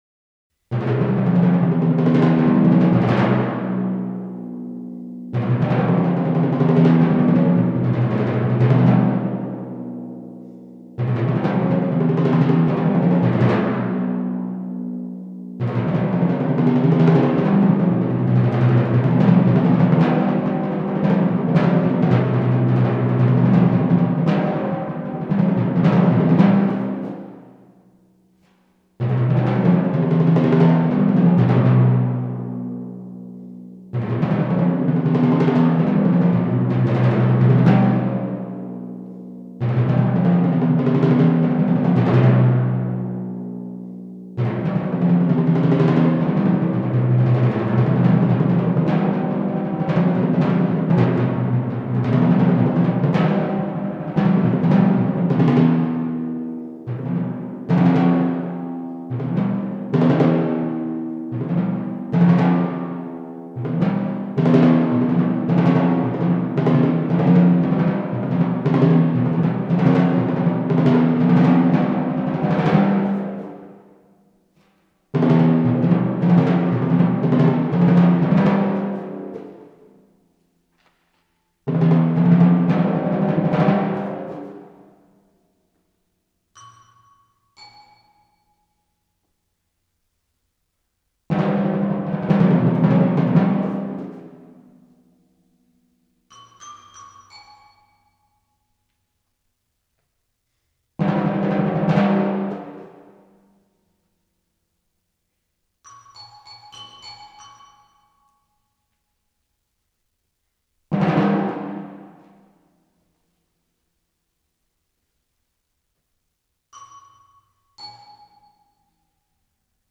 Voicing: Timpani Solo